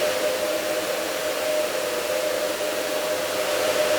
Index of /musicradar/sparse-soundscape-samples/Sample n Hold Verb Loops